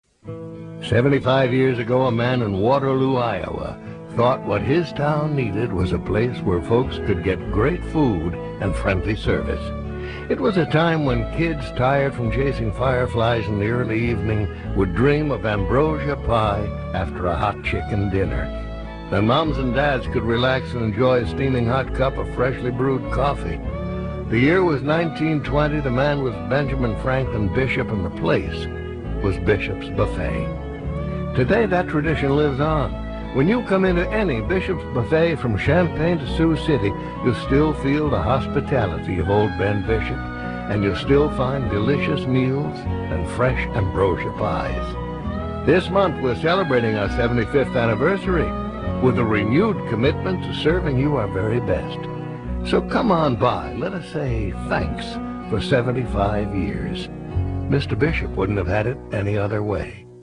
Various Radio Commercial Work